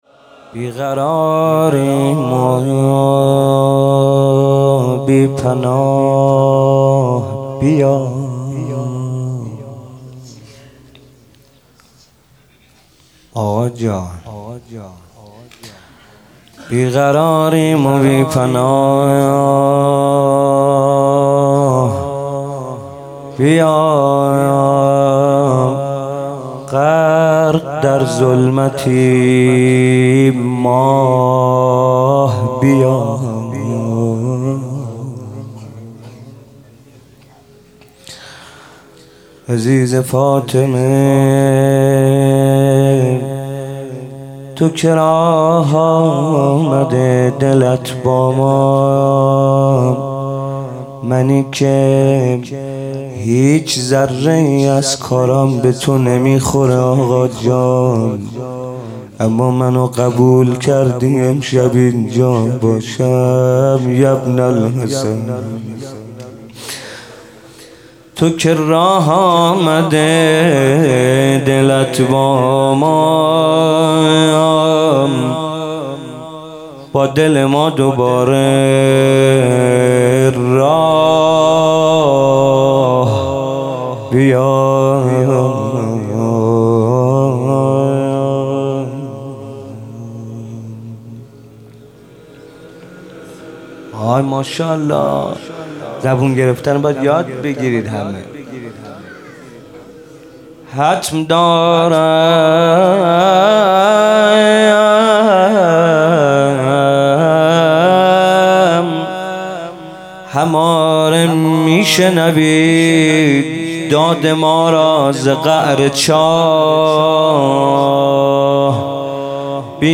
تخریب بقیع98 - مناجات